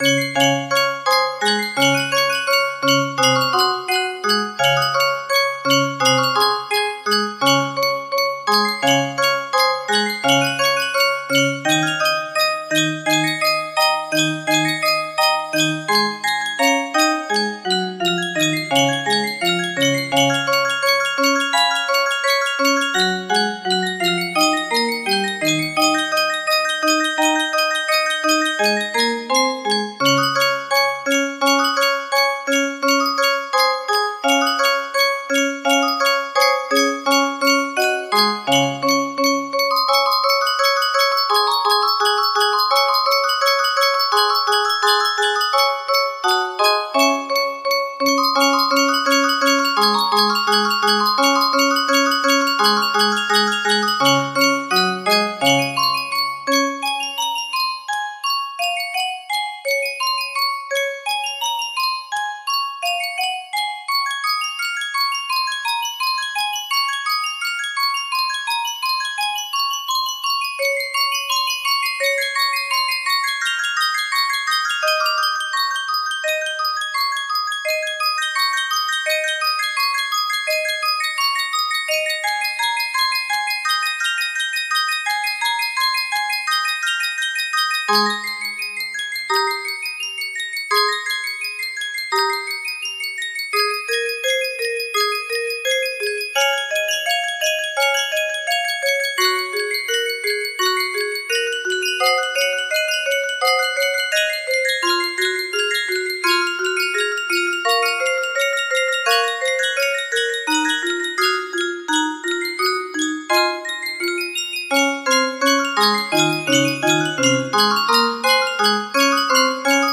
Vivaldi - Musicbox Concerto in G Major, RV 532, 1st Movement music box melody
Full range 60